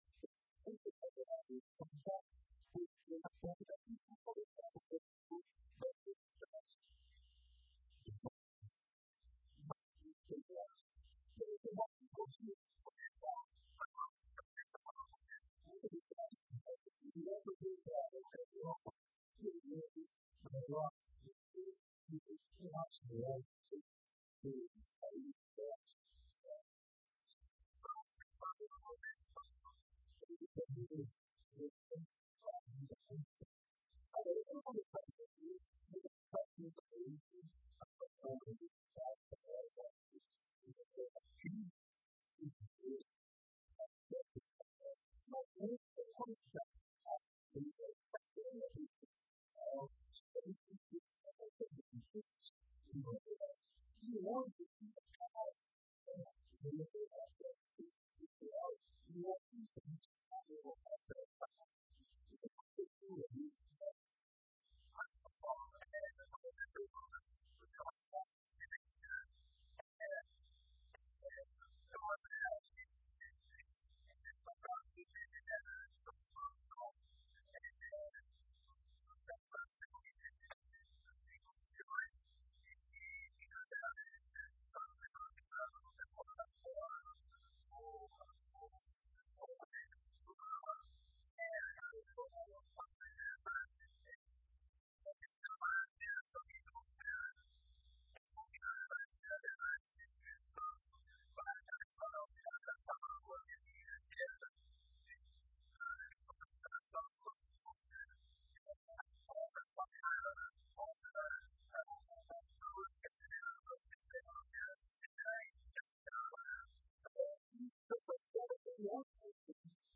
Entrevista no Programa Faixa Livre sobre as crises de Mariana e da Uenf